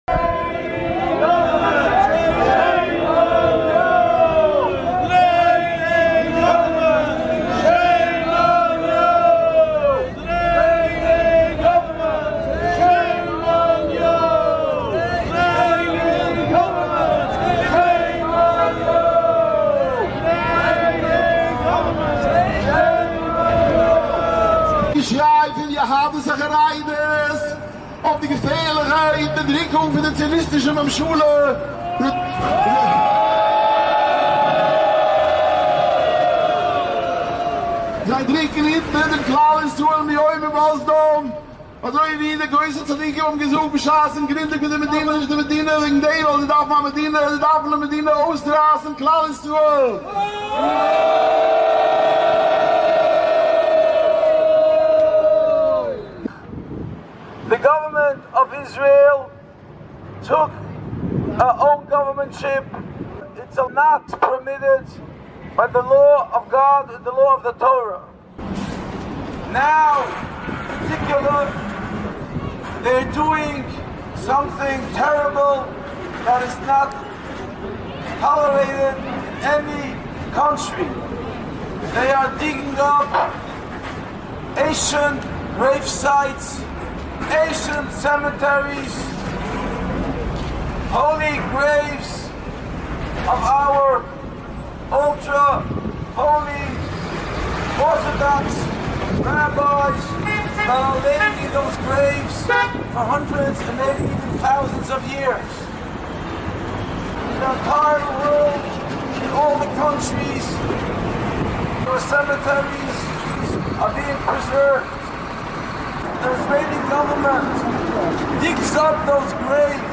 מחאת קודש מול בניין הקונסוליא הציונית במנהטן - עם הסברים בעברית